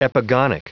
Prononciation du mot epigonic en anglais (fichier audio)
Prononciation du mot : epigonic